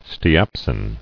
[ste·ap·sin]